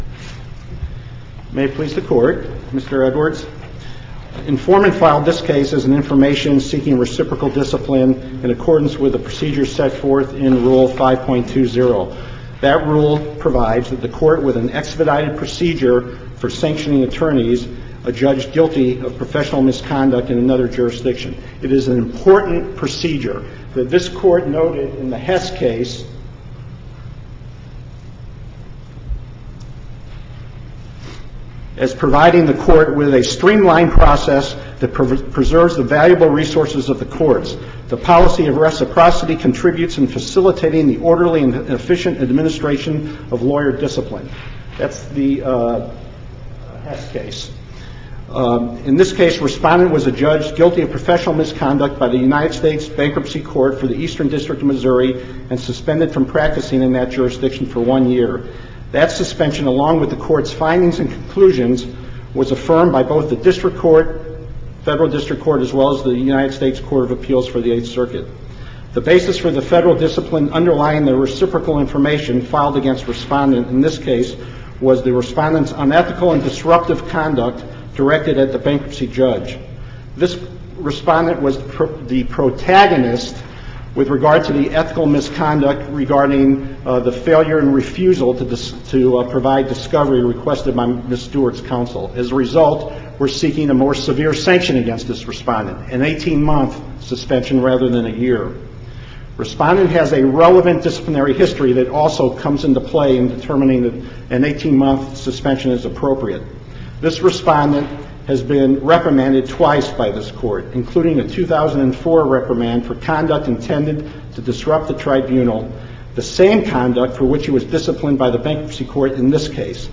MP3 audio file of oral arguments in SC96016